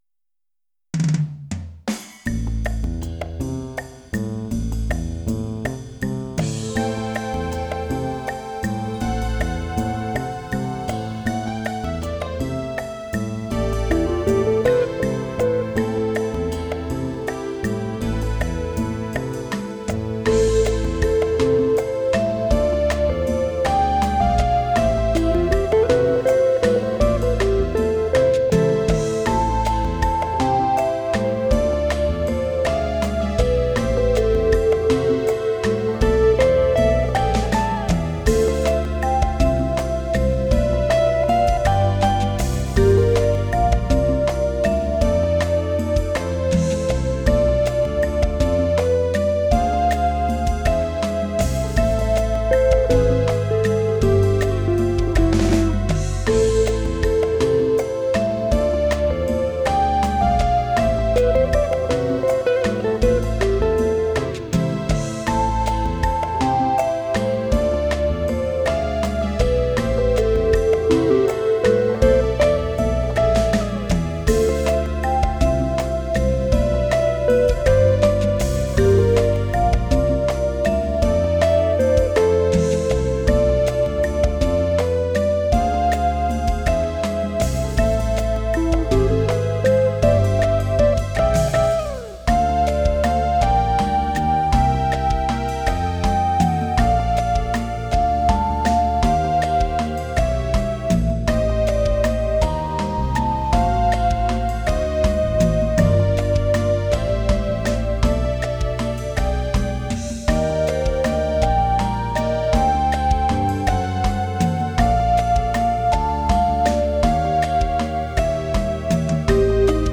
Jazzwaltz